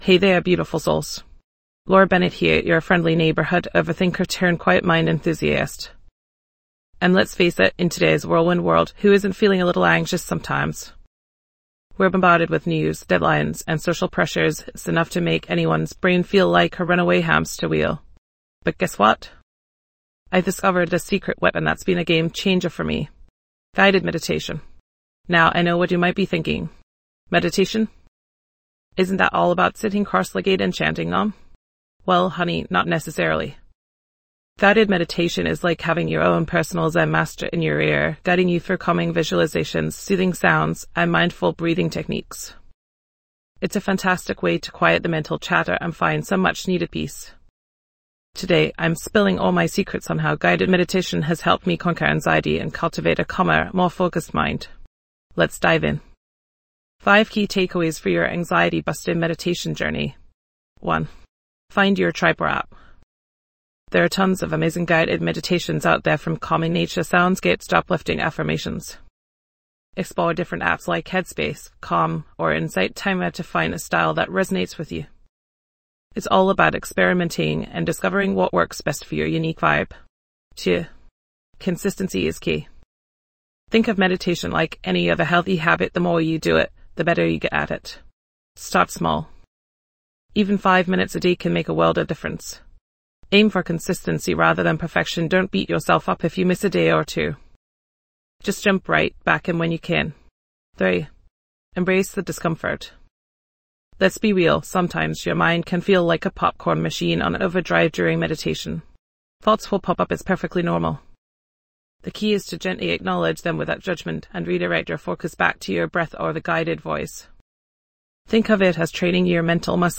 Key Takeaways:. - Understand the benefits of guided meditation in reducing anxiety - Gain practical tips on incorporating meditation into daily routine - Experience a calming guided meditation session to promote relaxation
This podcast is created with the help of advanced AI to deliver thoughtful affirmations and positive messages just for you.